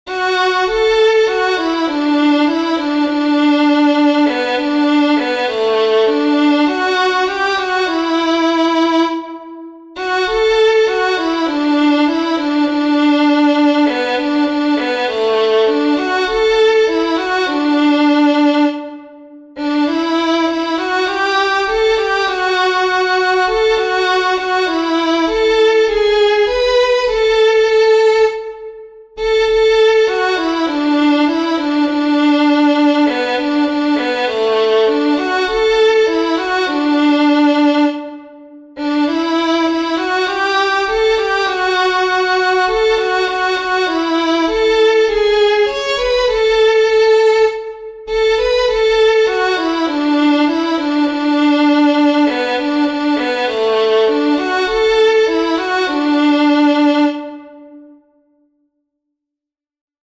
Here's sheet and a synthesized mp3 version for play-along to this fine old tune....   I chose to score it in D although I believe it is more commonly played in F.   If anyone wants the score transposed to a different key, just ask....
I left it as "violin" for the play-along, largely because it is a voice with 'sustain' and the note played lasts-as-long as it is scored.